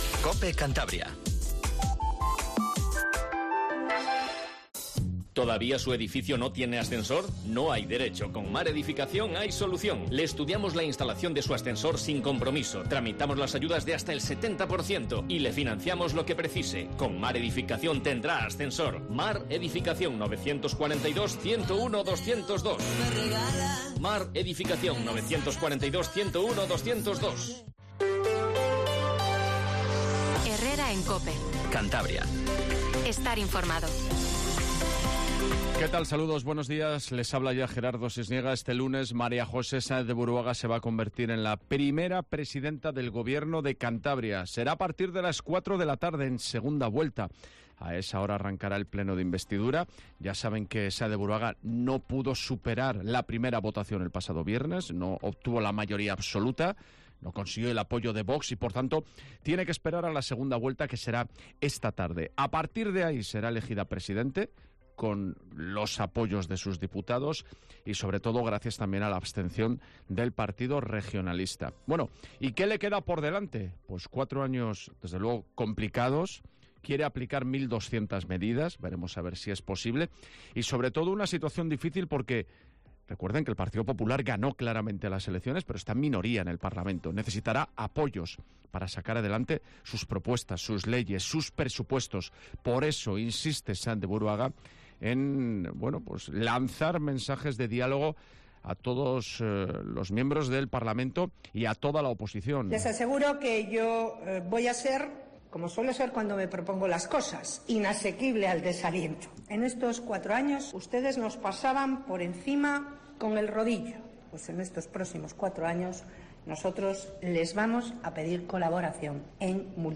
Informativo Matinal Cope 07:50